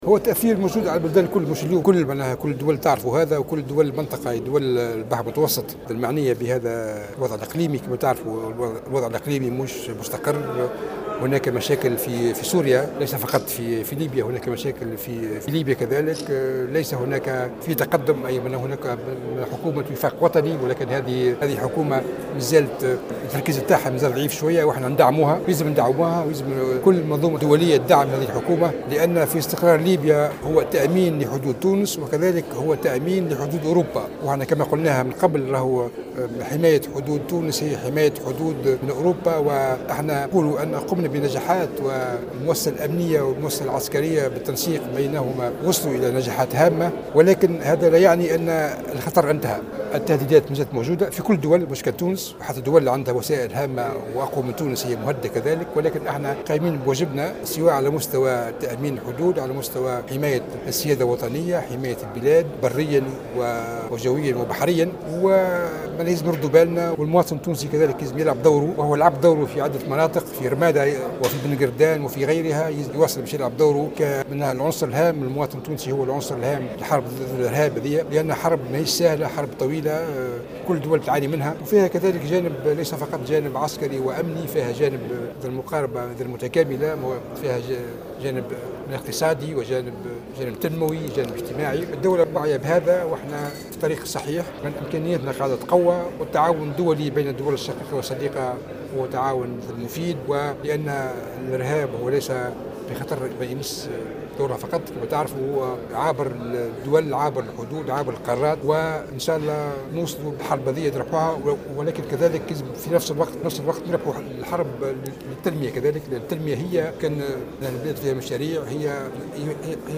علق وزير الدفاع الوطني فرحات الحرشاني خلال إشرافه صباح اليوم الخميس 29 سبتمبر 2016 على افتتاح ندوة بخصوص"انطلاق عقد برنامج البحث للمركز الوطني لرسم الخرائط والإستشعار عن بعد للفترة 2015- 2018 على آخر التطورات في ليبيا خاصة بعد هزيمة تنظيم داعش الارهابي في سرت.